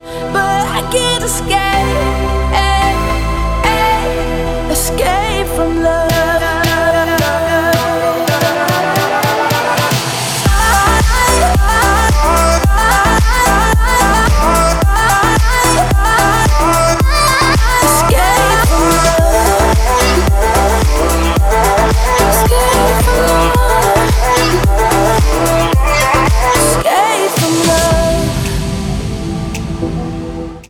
• Качество: 128, Stereo
атмосферные
заводные
dance
Electronic
EDM
быстрые
Зажигательная, классная, атмосферная музыка